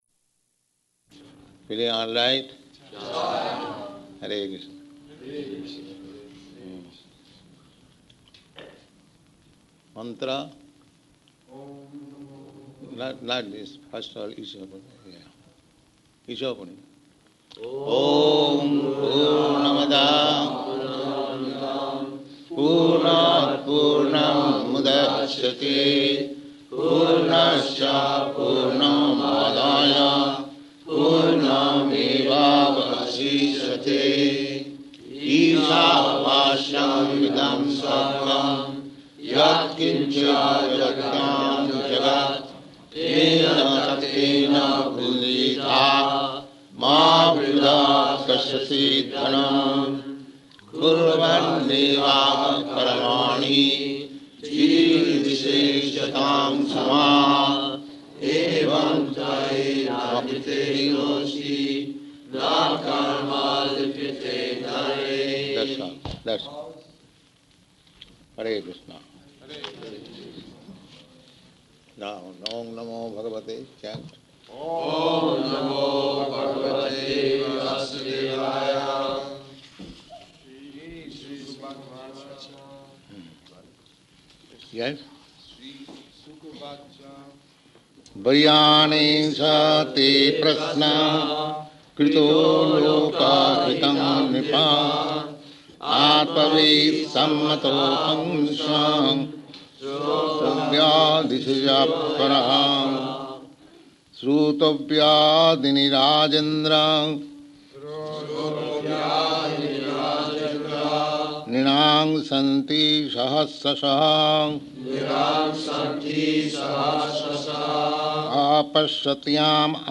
Śrīmad-Bhāgavatam 2.1.1–4 --:-- --:-- Type: Srimad-Bhagavatam Dated: April 10th 1969 Location: New York Audio file: 690410SB-NEW_YORK.mp3 Prabhupāda: Feeling all right?
[Prabhupāda leads devotees in chanting SB 2.1.1–5]